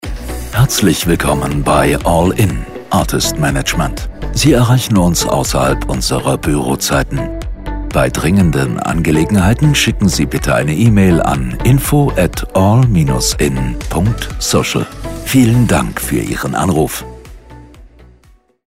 Telefonansagen mit echten Stimmen – keine KI !!!
ALL IN Management: Anrufbeantworter Ansage